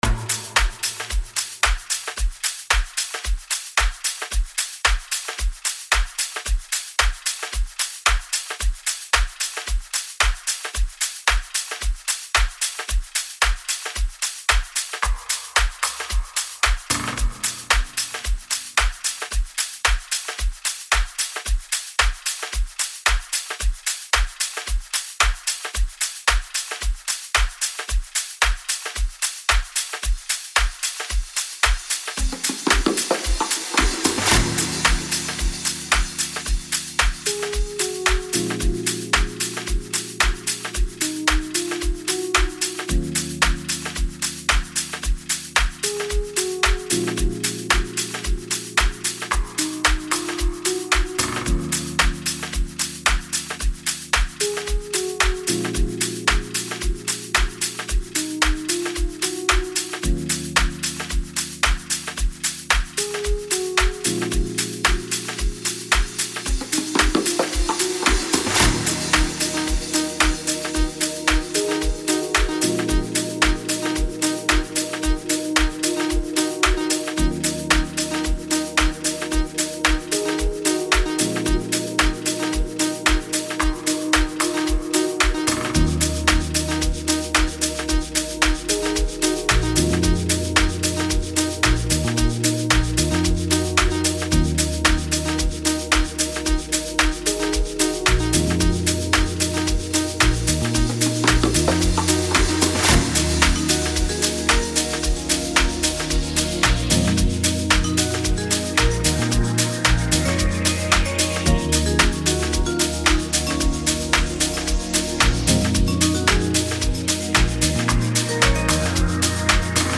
Genre : Amapiano